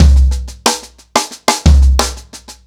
• 121 Bpm Drum Loop Sample C Key.wav
Free breakbeat sample - kick tuned to the C note.
121-bpm-drum-loop-sample-c-key-W0k.wav